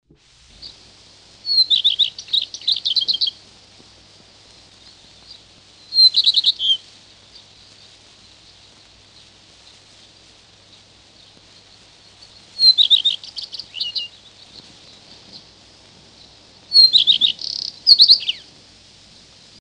1935 erschienen im Verlag von Hugo Bermühler in Berlin-Licherfelde insgesamt drei Schallplatten mit dem Titel „Gefiederte Meistersänger“, die die Singstimmen von Vögeln dokumentieren.
Der Gartenrotschwanz